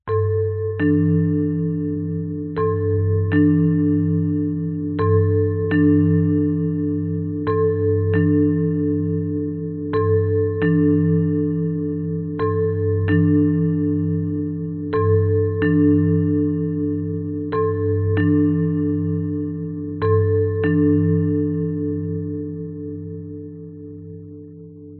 抽象的鼓包 " 吱吱嘎嘎的金属声 2
描述：被摩擦的金属声
标签： 打击乐器 抽象的 金属 摩擦 混凝土
声道立体声